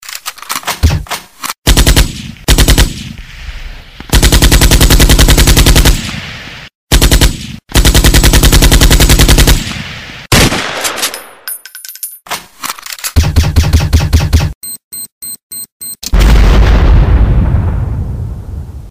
shoot-sounds_25083.mp3